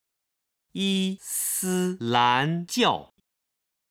イスラム教に関する中国語音源